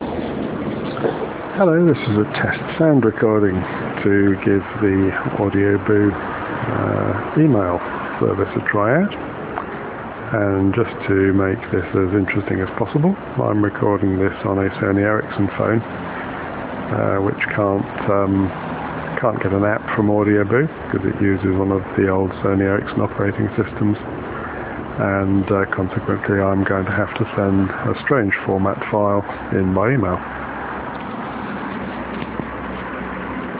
Testing BooMail with an audio file captured using a not so smart phone